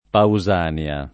[ pau @# n L a ]